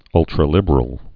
(ŭltrə-lĭbər-əl, -lĭbrəl)